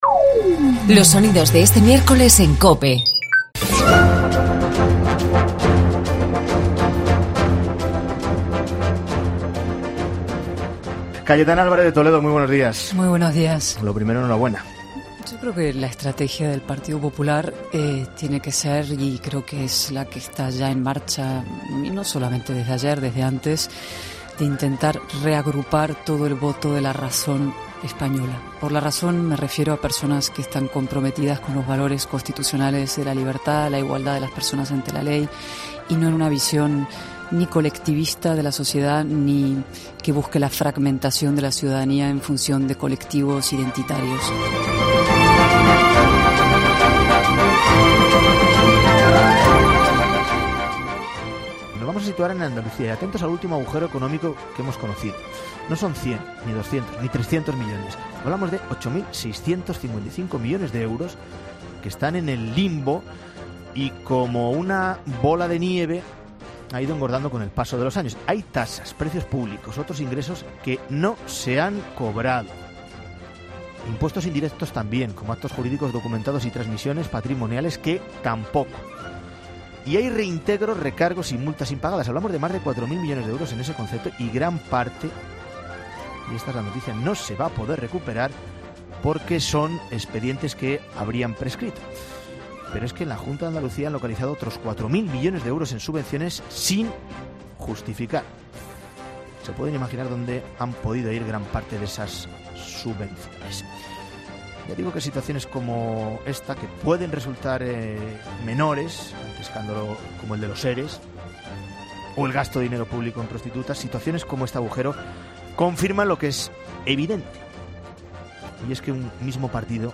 La entrevista a Cayetana Álvarez de Toledo entre los mejores sonidos del día en COPE
Además, ha sido entrevistada Cayetana Álvarez de Toledo, la nueva portavoz del PP en el Congreso.